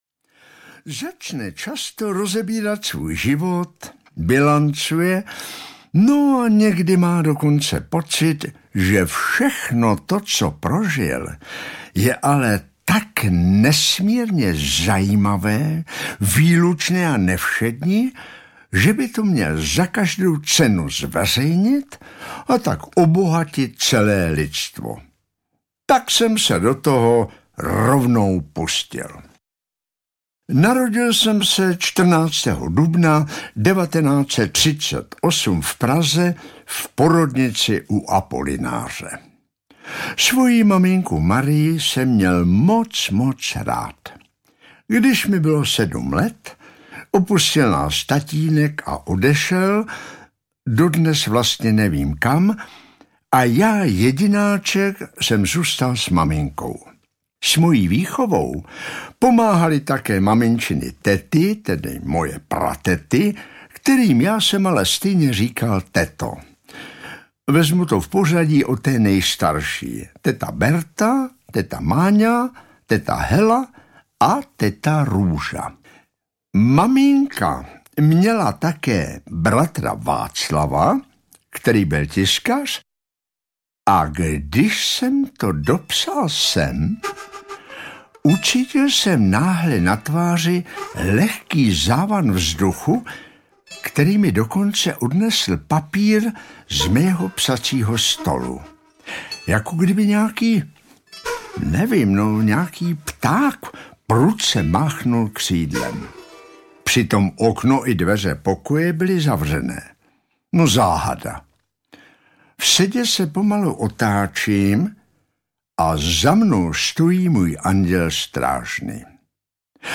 Ukázka z knihy
Nyní ale napsal a zejména sám namluvil na dvojdesku obsáhlý výběr svých textů, úvah a drobných vzpomínek.
• InterpretPetr Nárožný